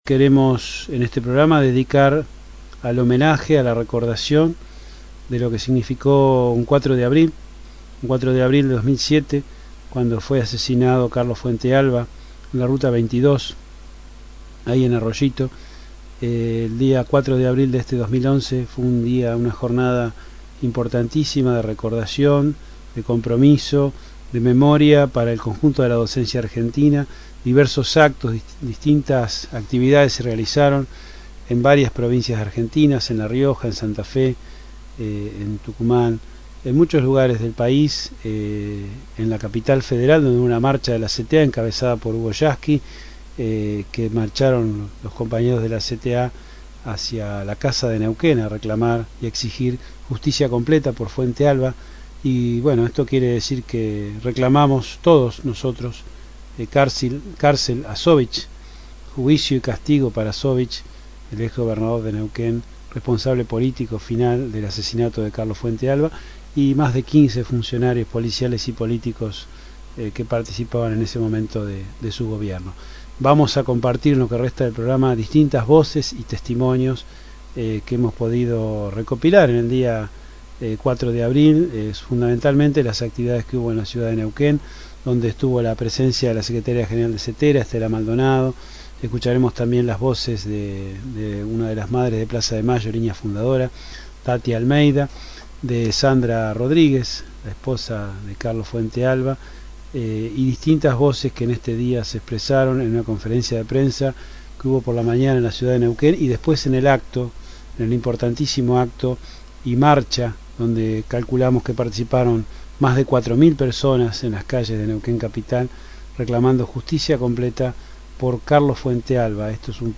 media 05/04/11 Testimonios Conferencia de Prensa y Marcha Justicia Completa por Carlos Fuentealba, 4 de abril de 2011, Ciudad de Neuquén Más de 6000 personas marcharon el 4 de abril en horas de la tarde, reclamando justicia completa por Carlos Fuentealba, por las calles de la ciudad de Neuquén.